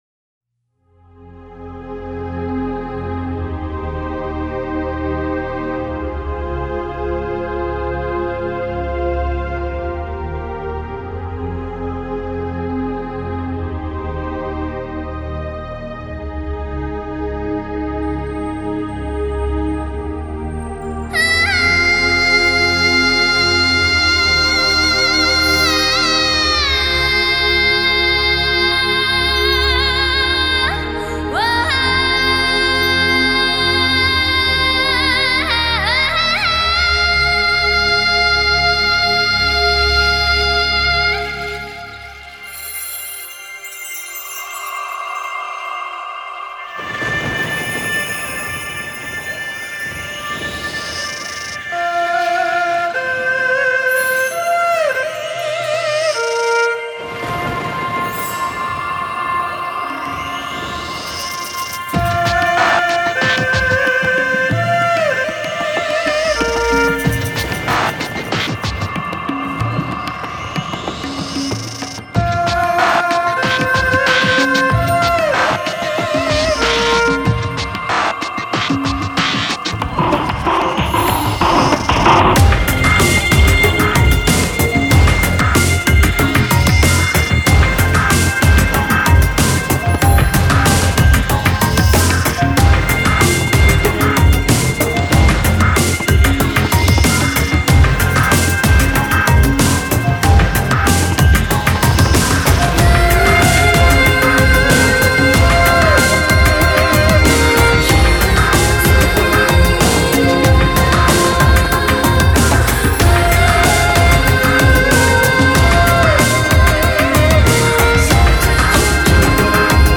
民族風大行其道，
将藏族特有的风味完美无瑕地融合于J-POP中，
同时也保持着浓郁的民族风味，